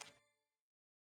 map_open_updated_2_haas.wav